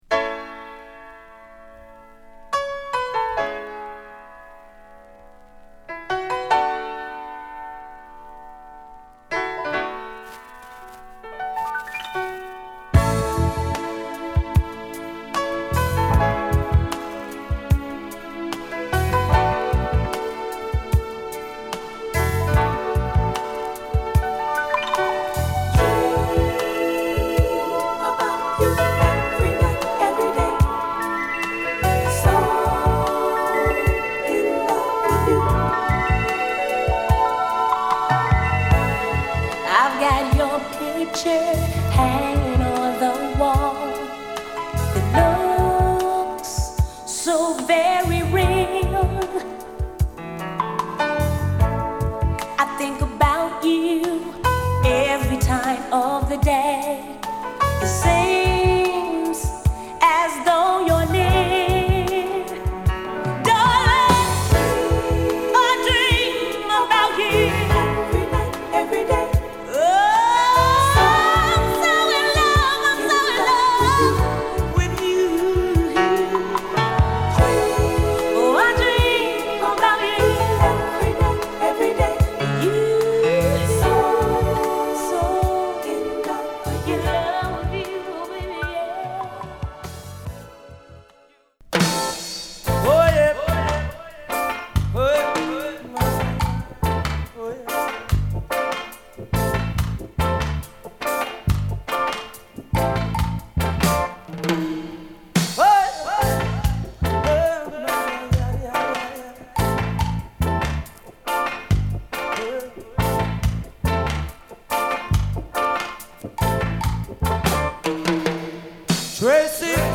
ピアノとシンセストリングスを軸にしたクワイエット・ストームなバックに男女混合のヴォーカルが乗るアーバンメロウ！